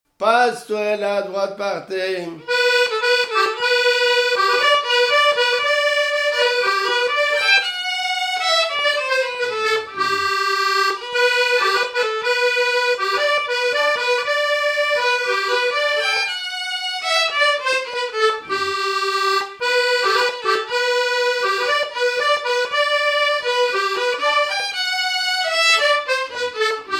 Mémoires et Patrimoines vivants - RaddO est une base de données d'archives iconographiques et sonores.
Quadrille - Pastourelle
Pièce musicale inédite